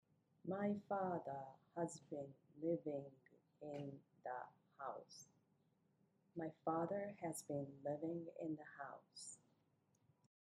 日本語風に平坦に言ったものと、
英語のリズムで言ったものを